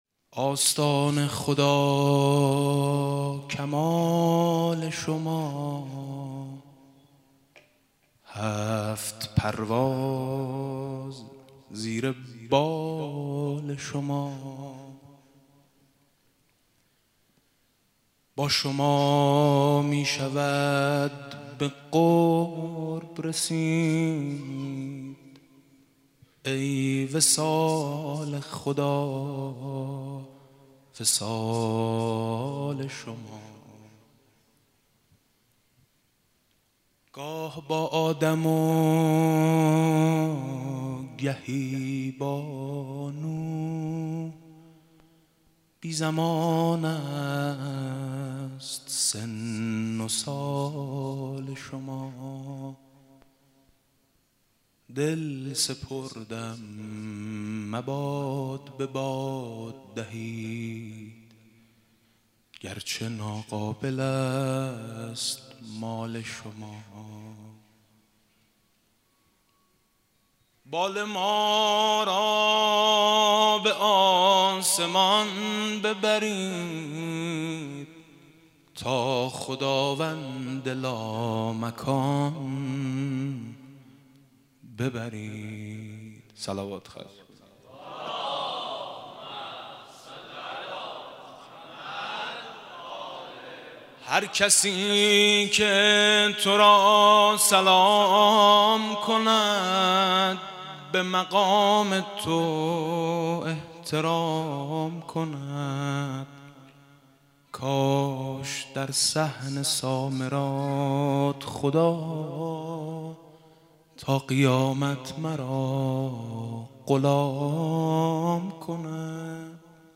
مولودی امام هادی (ع) با متن